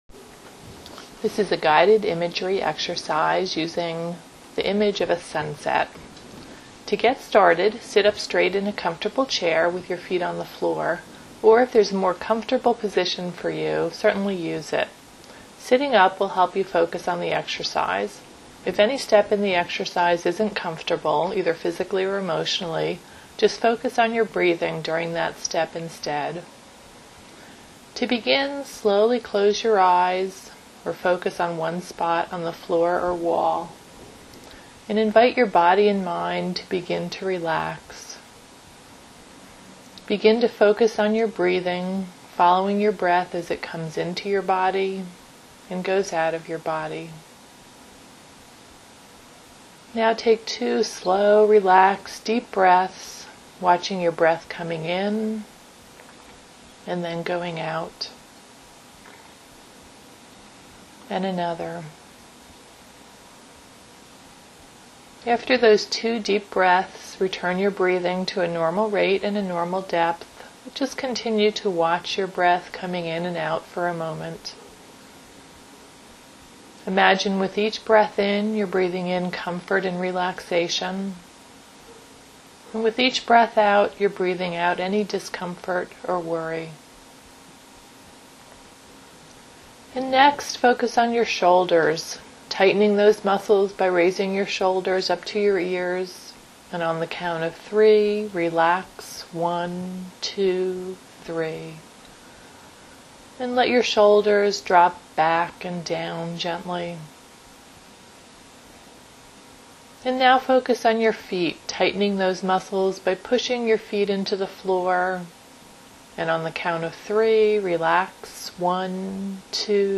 guided-imagery-sunset.wma